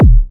Index of /90_sSampleCDs/Club_Techno/Percussion/Kick
Kick_08.wav